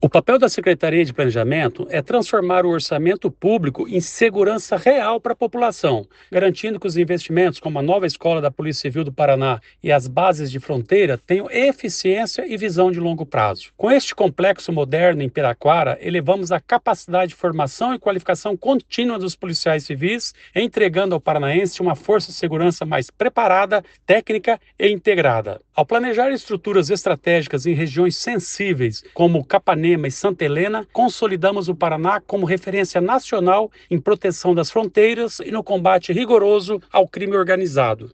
Sonora do secretário do Planejamento, Ulisses Maia, sobre o anteprojeto anteprojeto para construção da Nova Escola da PCPR em Piraquara